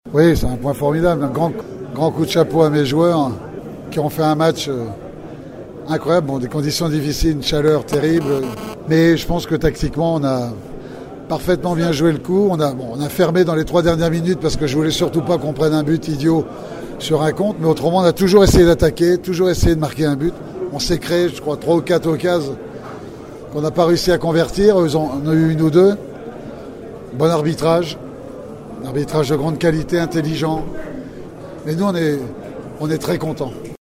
Geoffroy Serey Dié, le capitaine des Eléphants de Côte d'Ivoire